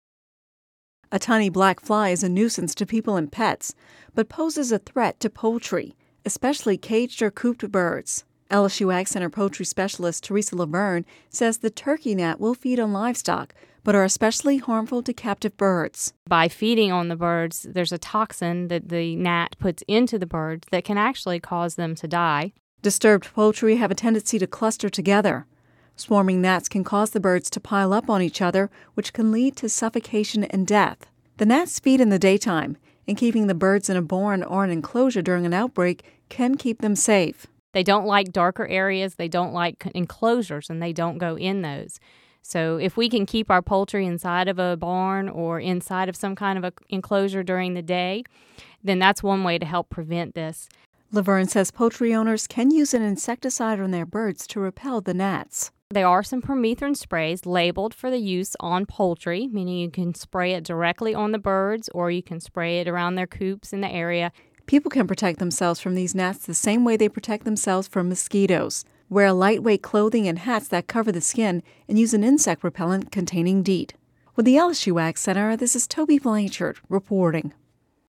(Radio News 05/04/11) A tiny black fly is a nuisance to people and pets, but this gnat poses a threat to poultry.